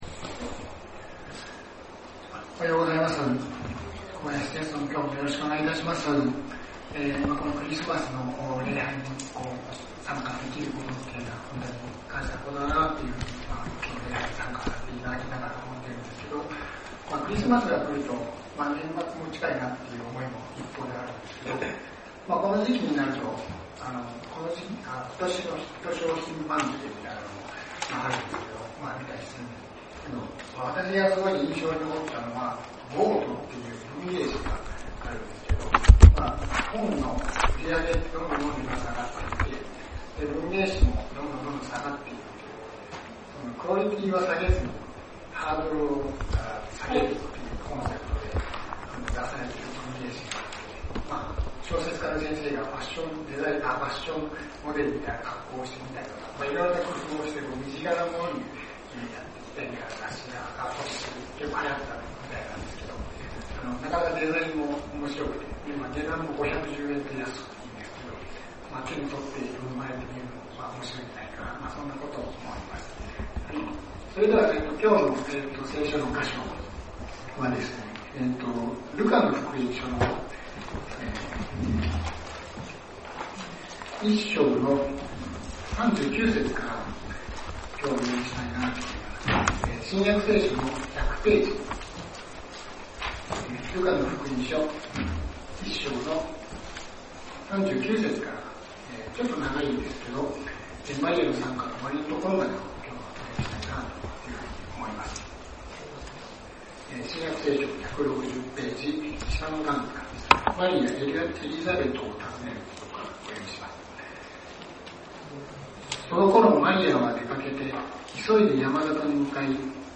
先週，東京集会で行わ れた礼拝で録音された建徳です。